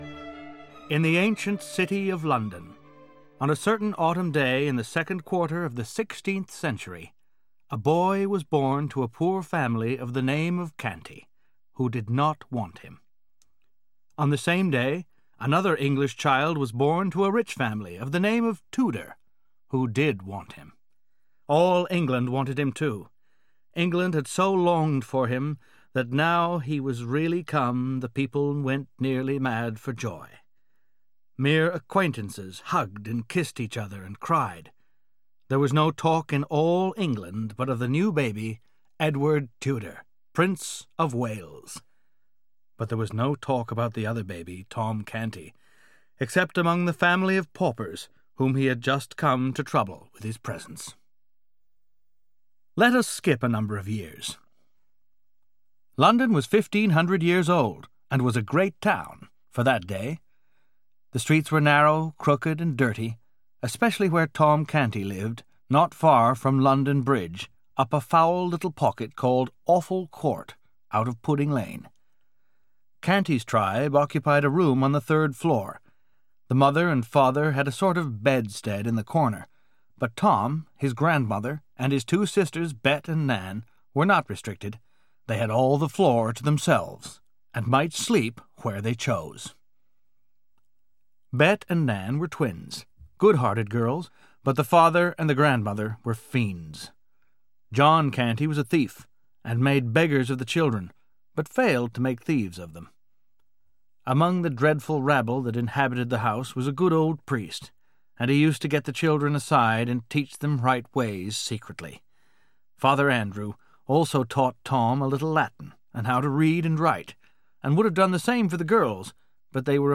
The Prince and The Pauper - Mark Twain - Hörbuch